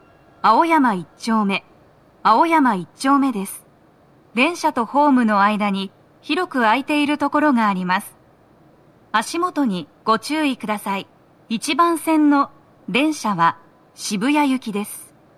スピーカー種類 TOA天井型
足元注意喚起放送が付帯されており、多少の粘りが必要です。
到着放送1